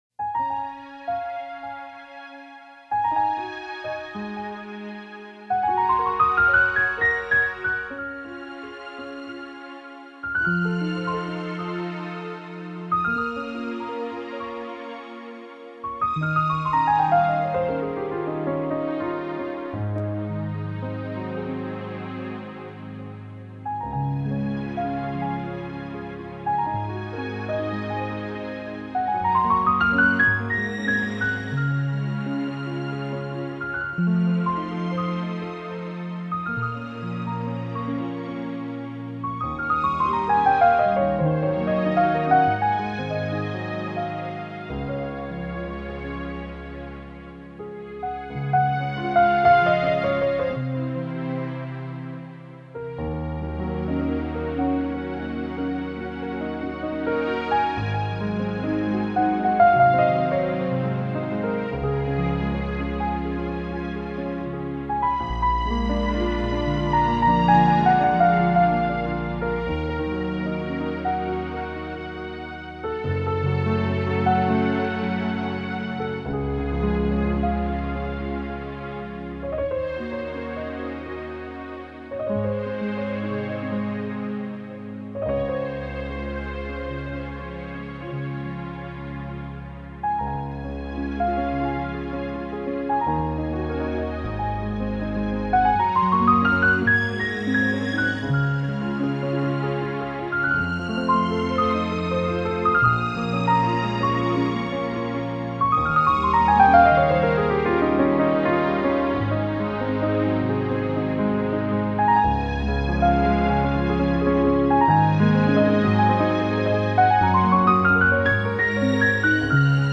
Genre:New Age, Instrumental, Neo-Classical, Piano